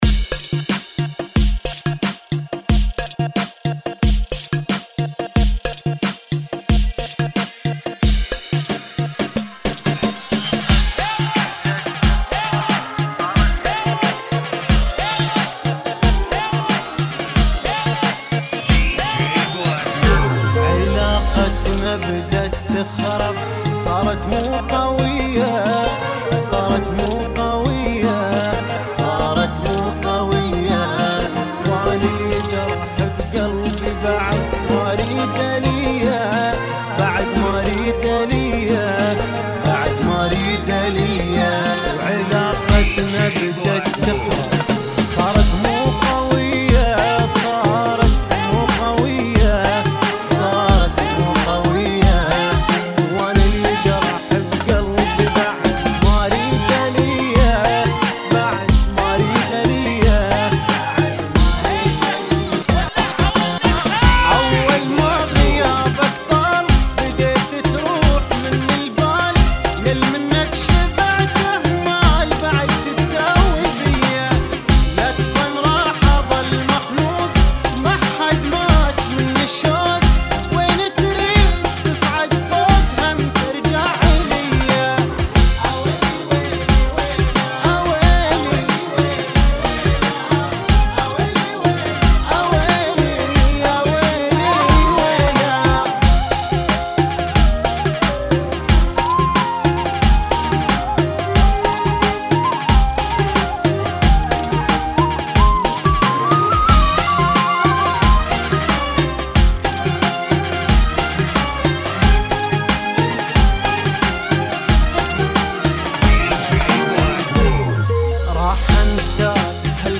[ 90 Bpm ]